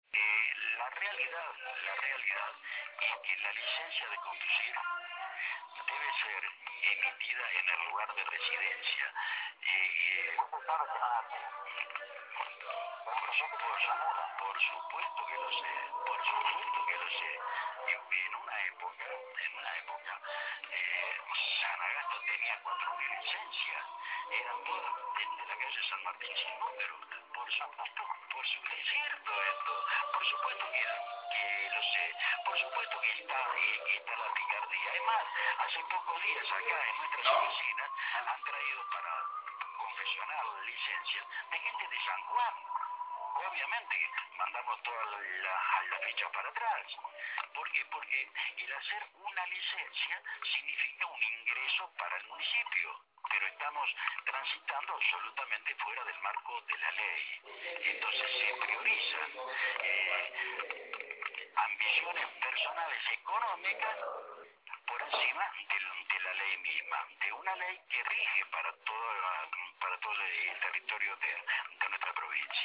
Walter Mena, delegado de la Agencia Nacional de Seguridad Vial, por Radio 105.1
Mena hizo el reconocimiento de la irregularidad en declaraciones a Radio 105.1, al decir que “hay puntos negros” en materia de seguridad vial.